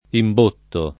imbottare v.; imbotto [ imb 1 tto ]